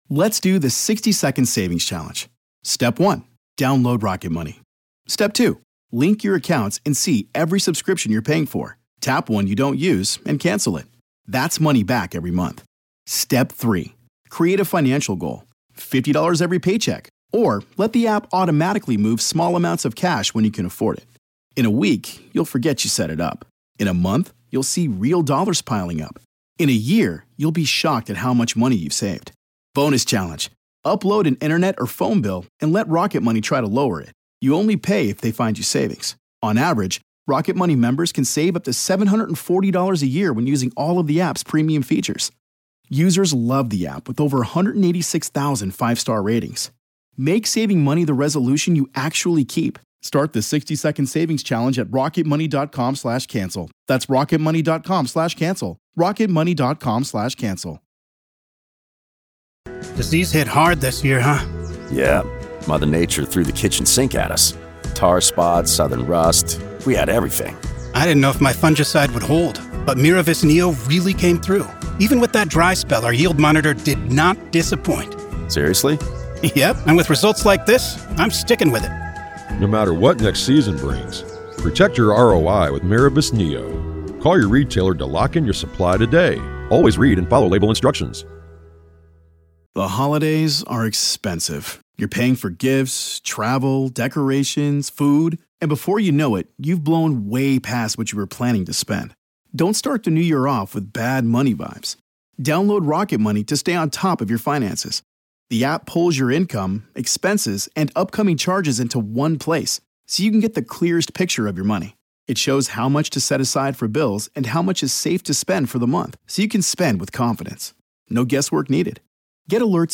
This conversation not only delves into the specifics of the Delphi case but also prompts a broader reflection on the complexities and challenges within criminal investigations.